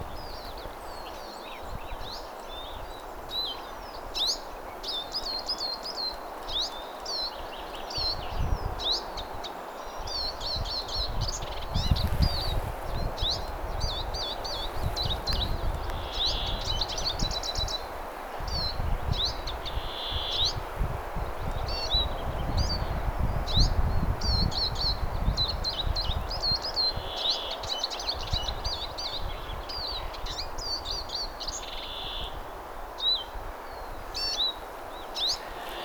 vihervarpunen laulaa
vihervarpunen_laulaa.mp3